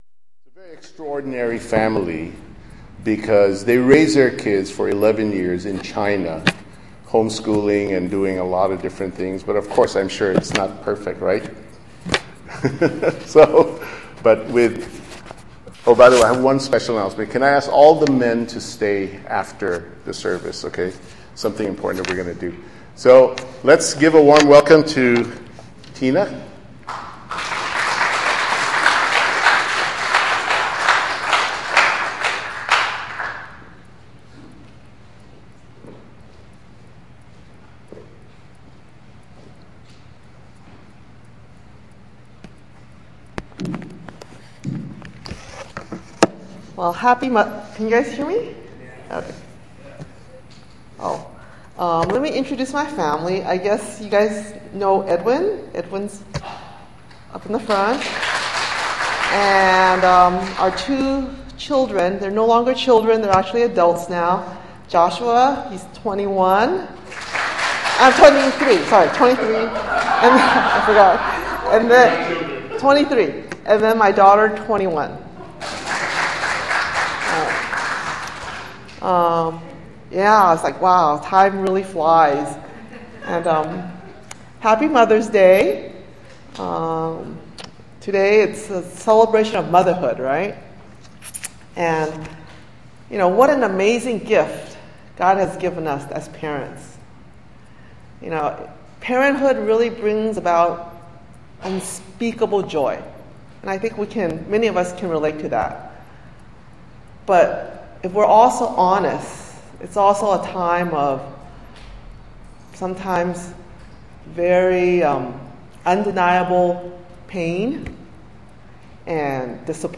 Sermon Topics: As for Me and My House,We Will Serve the Lord